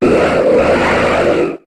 Cri de Tropius dans Pokémon HOME.